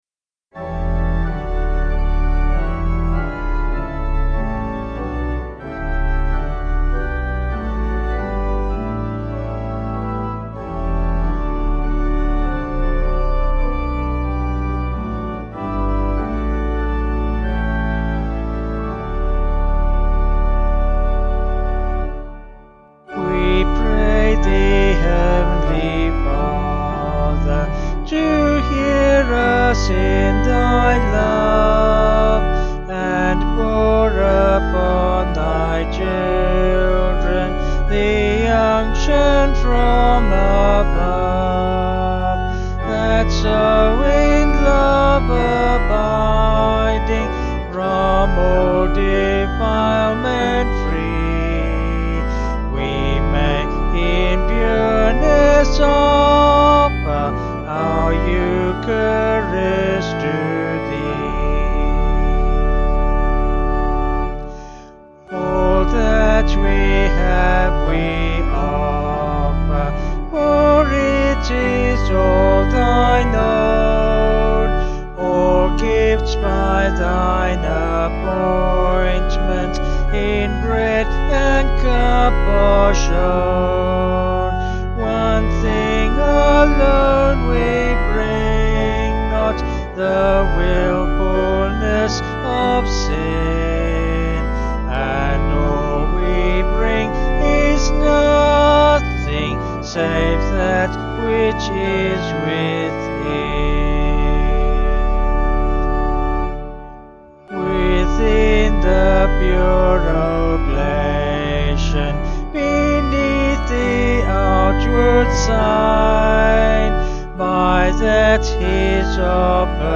Vocals and Organ   671.9kb Sung Lyrics